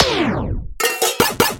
8位打击乐填充
Tag: 150 bpm Electronic Loops Percussion Loops 275.80 KB wav Key : C FL Studio